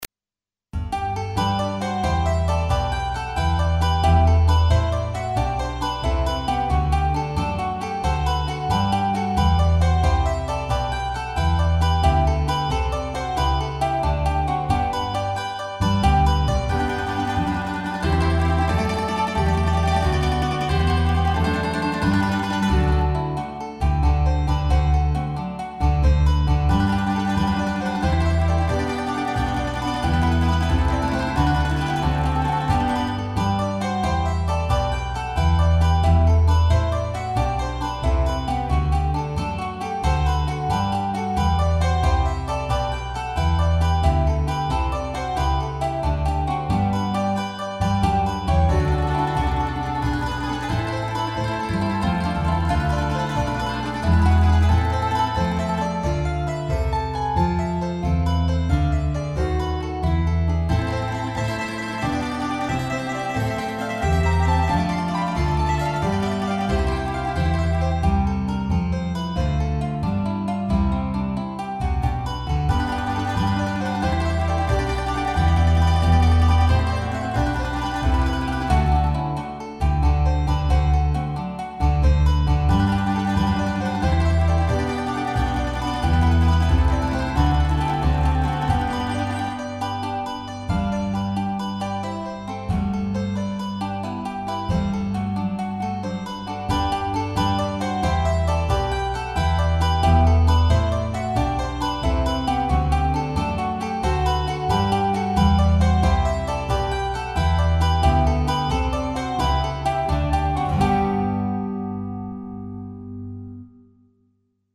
Jesus joy of man desiring (tr) J.S. Bach orchestra a plettro